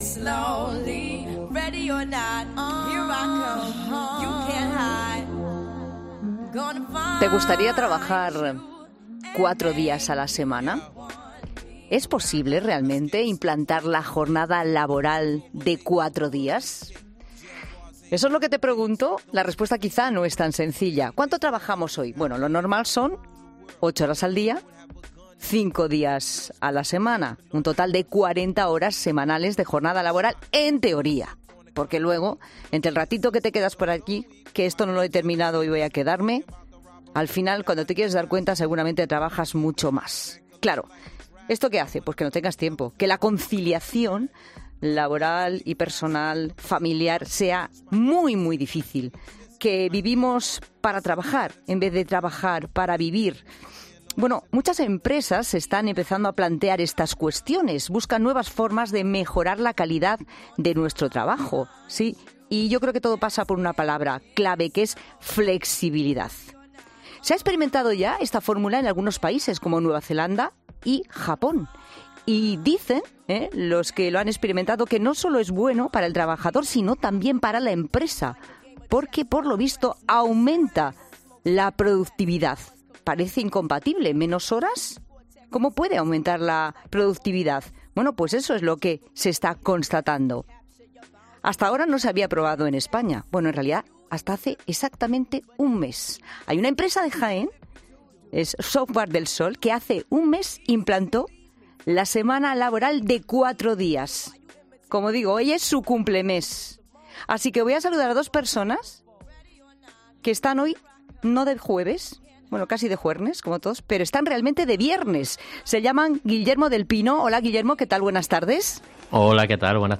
La primera empresa española en implantar 4 días de jornada laboral está ubicada en Jaén y hablamos con dos de sus trabajadores